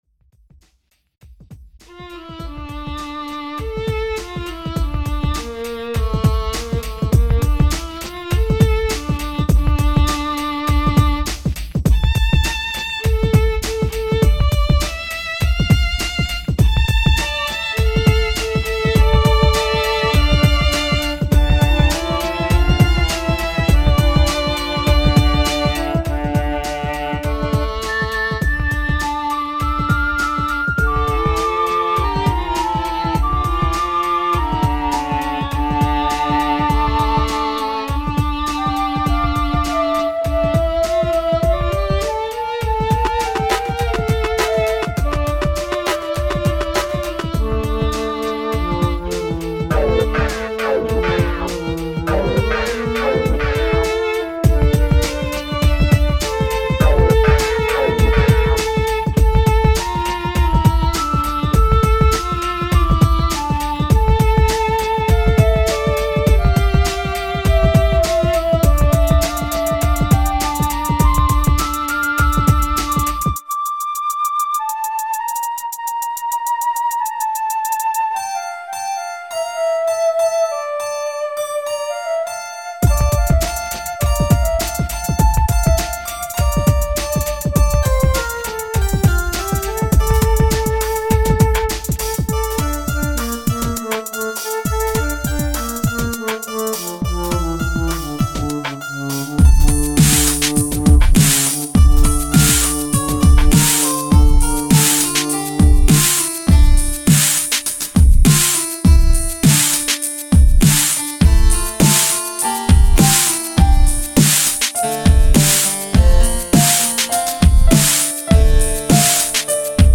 Posted in Classical, Dubstep Comments Off on